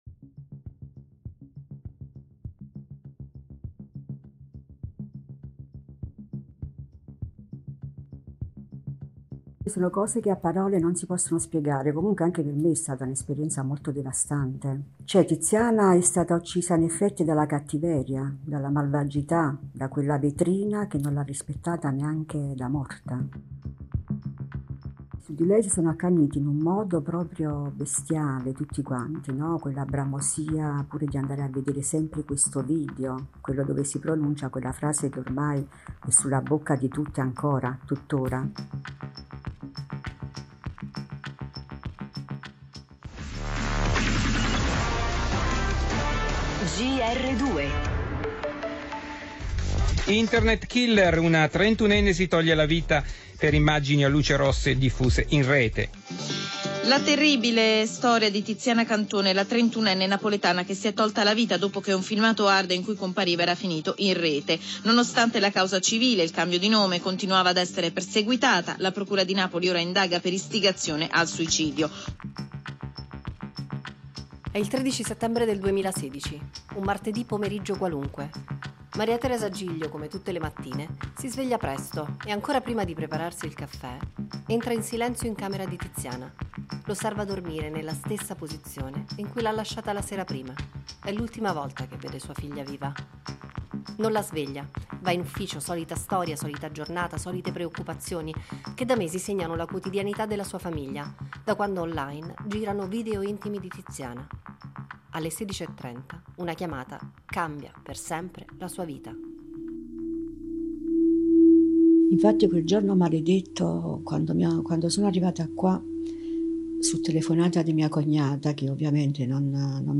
L’audio documentario